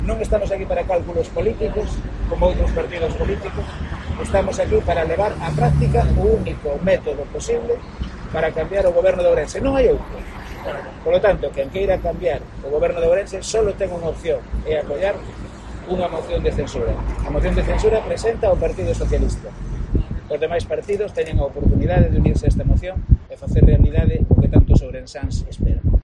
Declaraciones de Rafael Rodríguez Villarino sobre la moción de censura